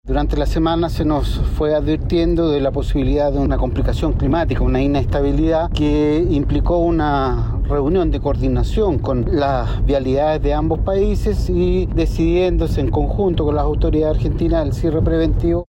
Asimismo, el delegado presidencial provincial de Los Andes, Cristian Aravena, informó sobre las reuniones llevadas a cabo con las autoridades argentinas para la toma de esta decisión temporal.